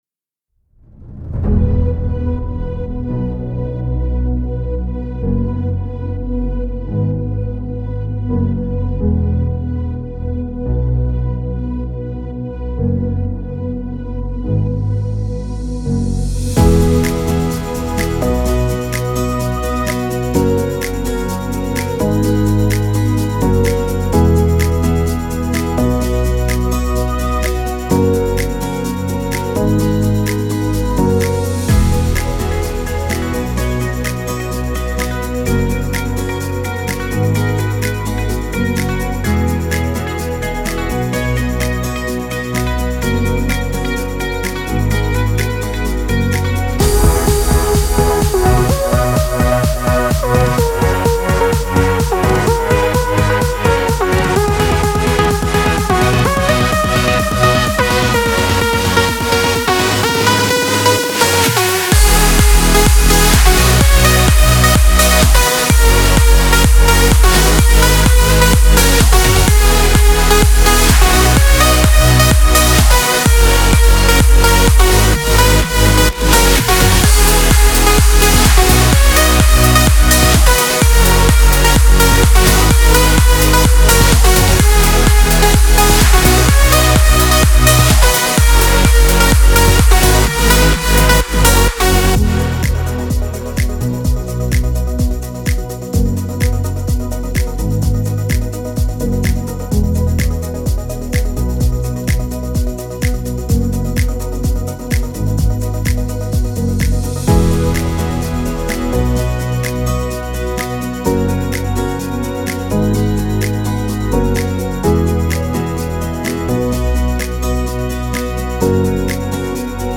Download Instrumental Version